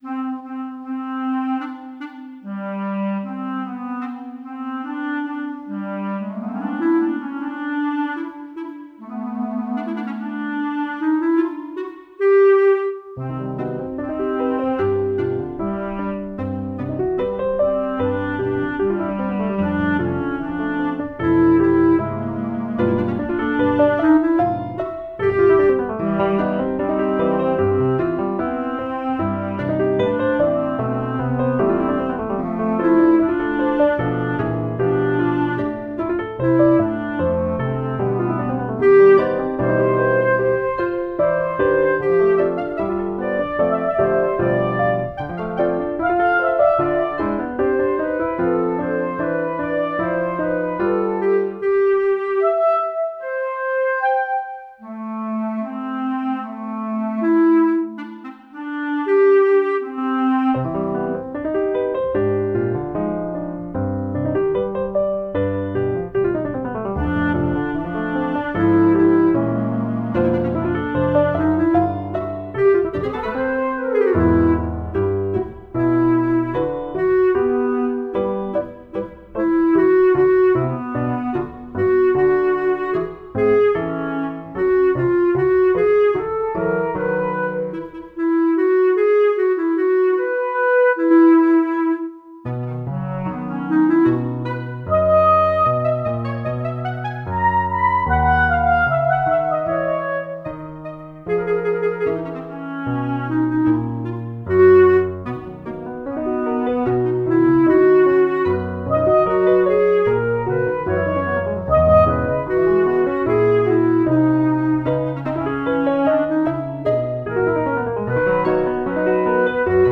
Chansonnettes pour Clarinette